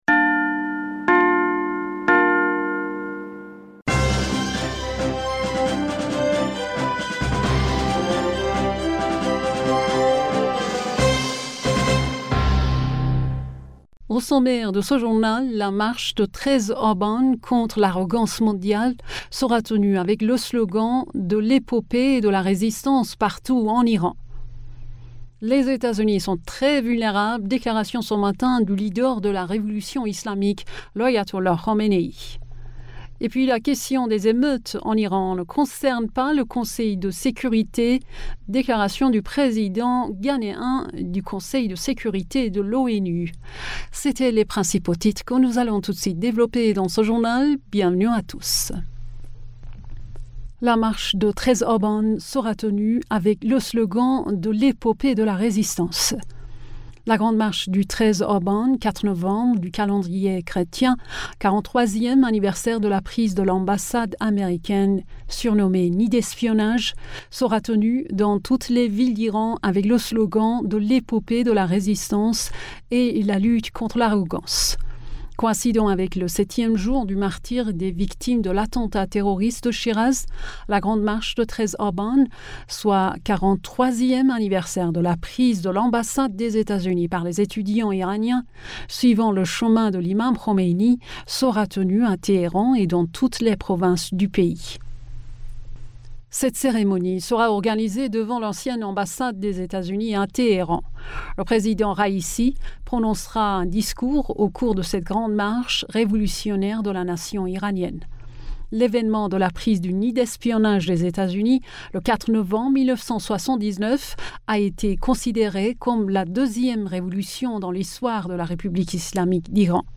Bulletin d'information Du 02 Novembre